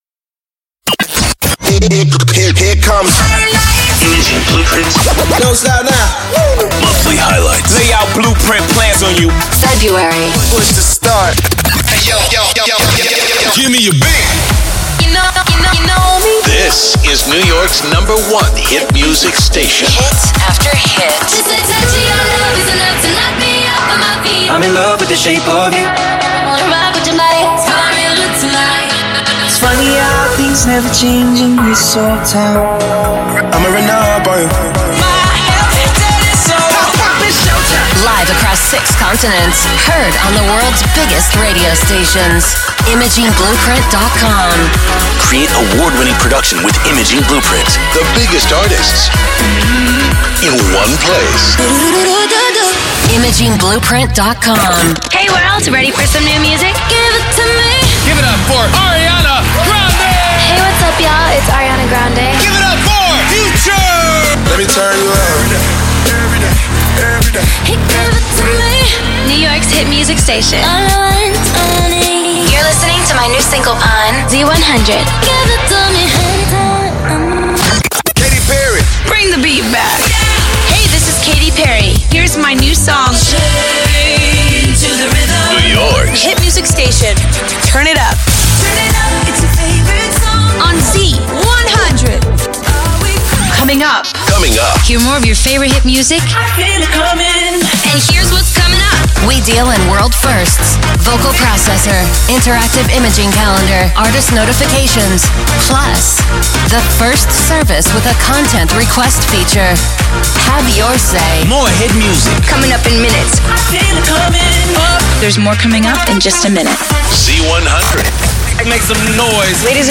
Our client KIIS is used to demonstrate 'IB' production alongside the world famous Z100.
It features an innovative, online database containing thousands of audio files including sound design, music beds, artist-imaging workparts, vocal work parts, topical elements and much much more!